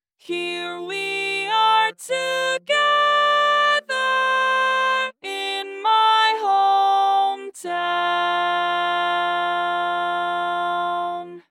Key written in: F Major
Type: Female Barbershop (incl. SAI, HI, etc)